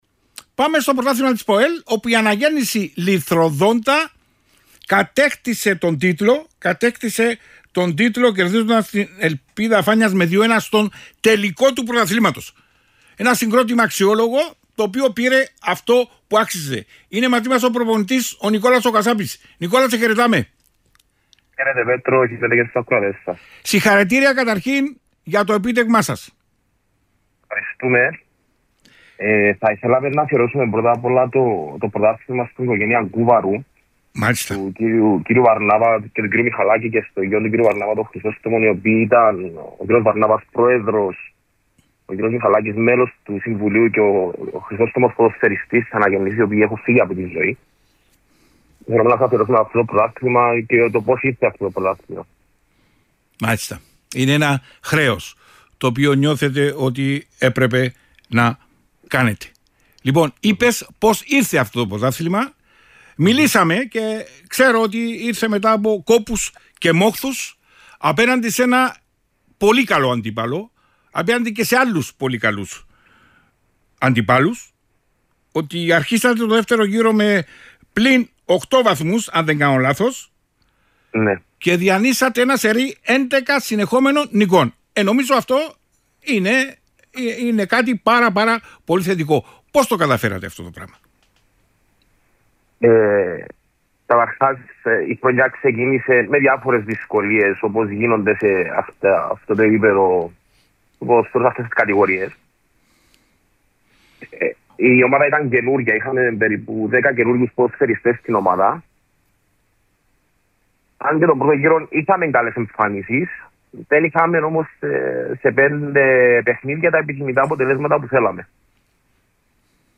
ΑΝΑΓΕΝΝΗΣΗ Λυθροδόντα: Oι ραδιοφωνικές δηλώσεις των Πρωταθλητών Α’ Κατηγορίας ΠΟΕΛ – GREE